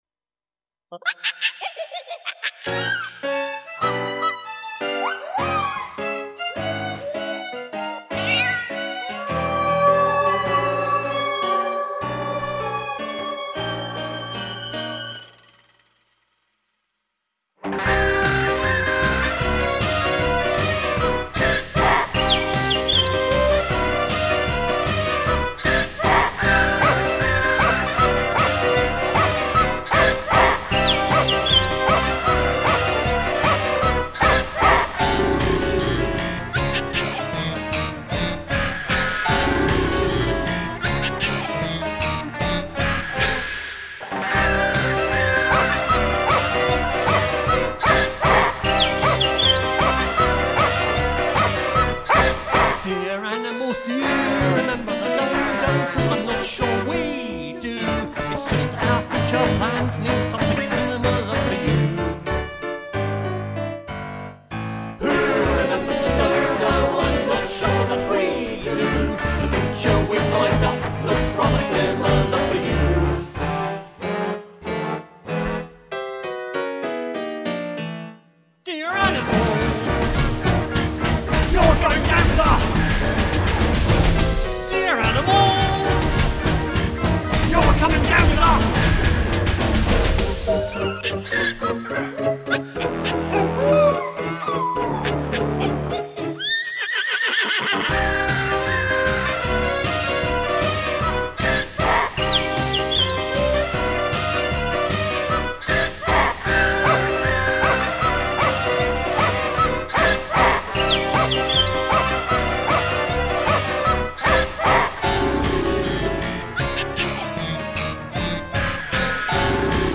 Guest Vocalists include:-
* A donkey
* A cockerel
* An elephant
* Rather a lot of sheep
* Some macaque monkeys
* Some northwestern wolves
* A lion
* A raven